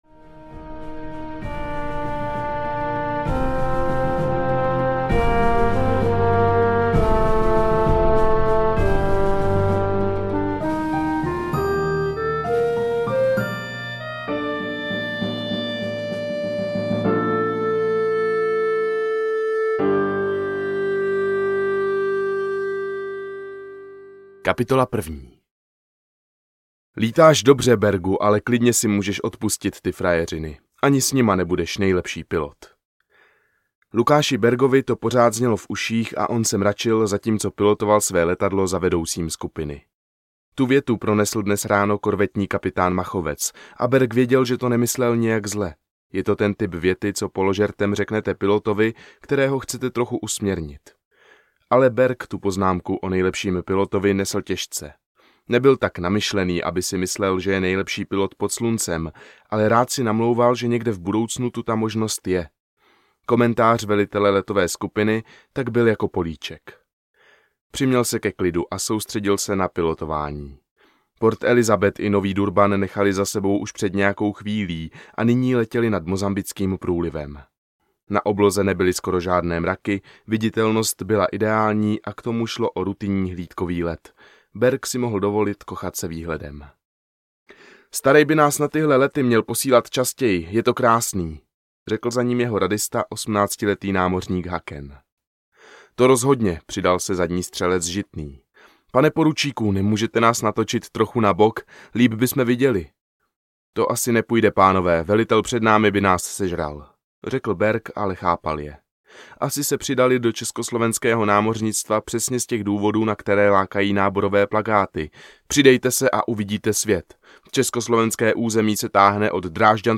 Nad Českými zeměmi slunce nezapadá audiokniha
Ukázka z knihy